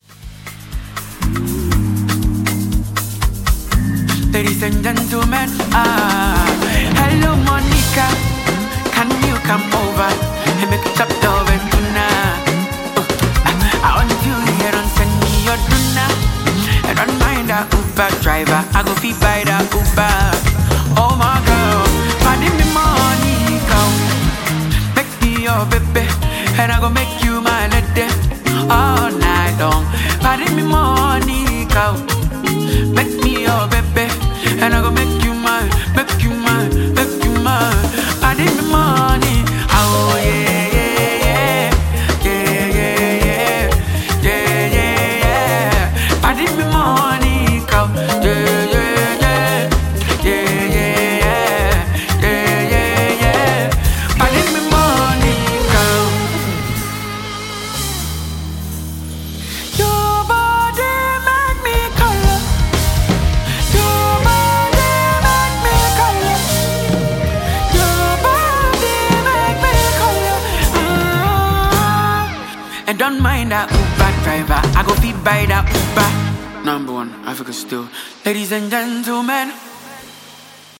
the live version